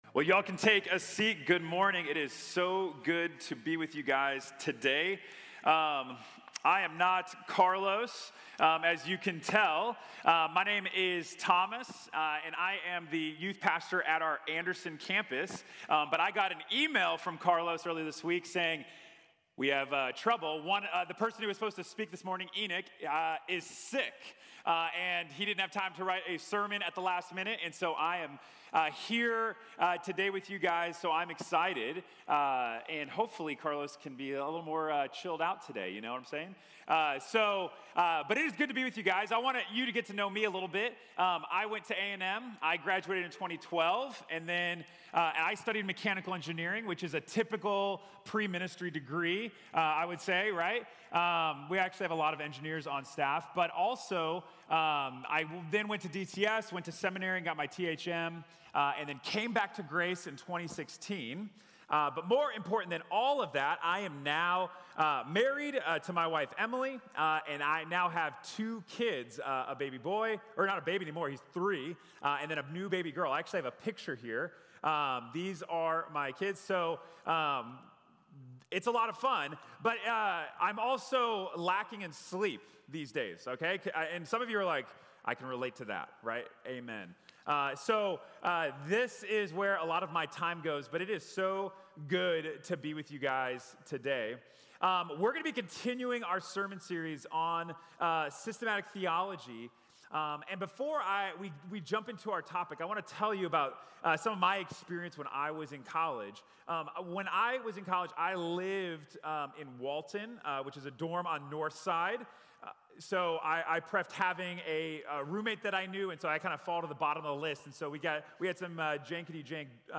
Angels and Demons | Sermon | Grace Bible Church